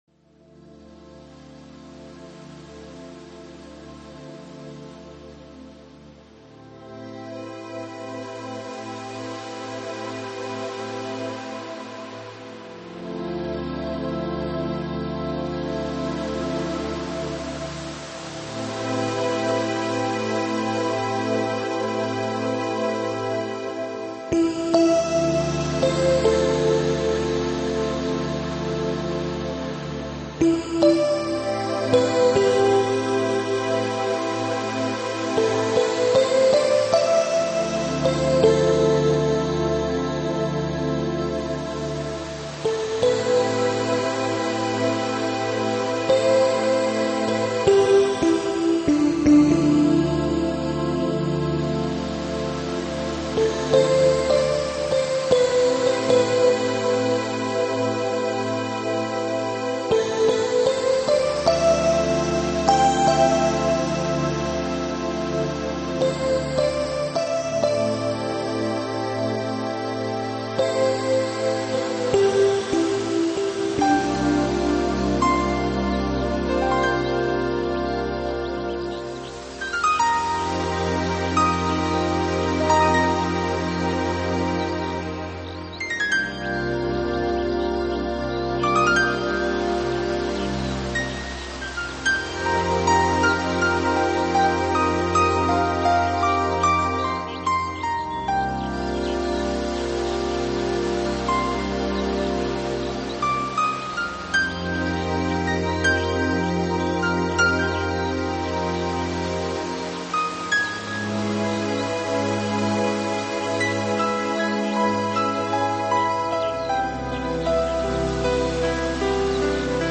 尽情倾听来自海涛的节奏与韵律......